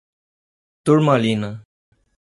Uitgespreek as (IPA) /tuʁ.maˈlĩ.nɐ/